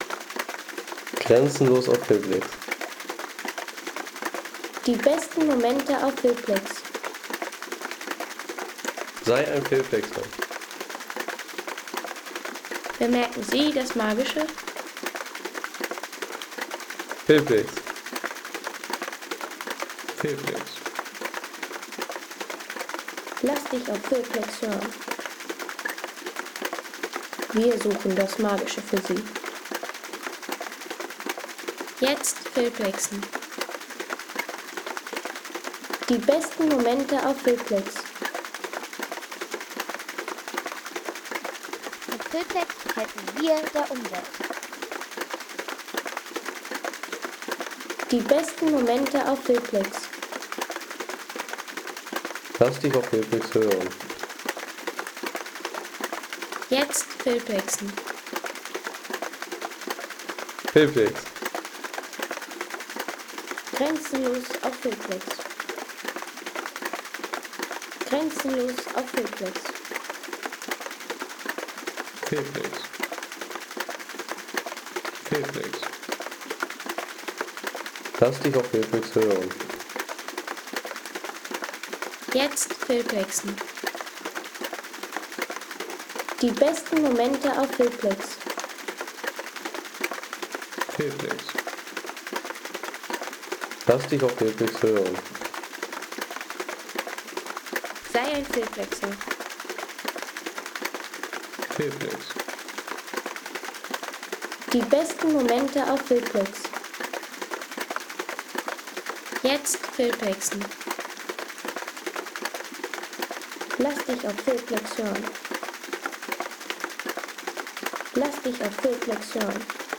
Wasserrad-Klopfer